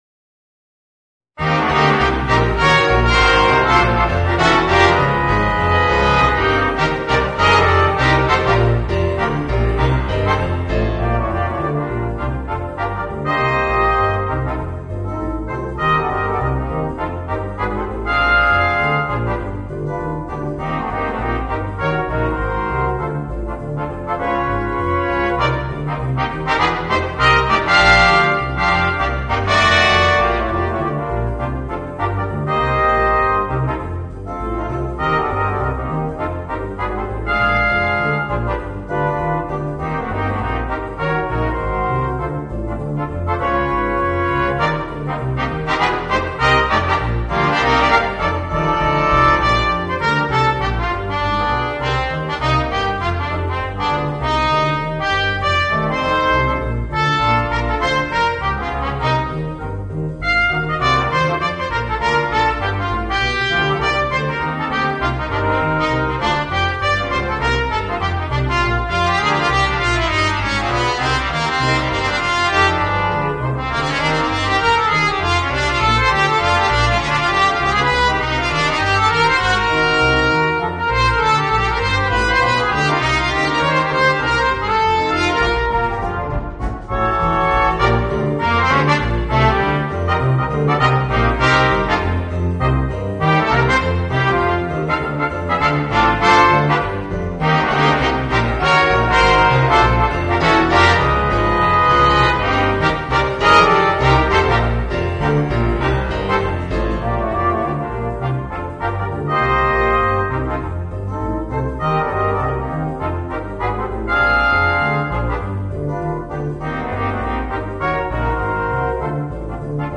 Voicing: 3 Trumpets and 2 Trombones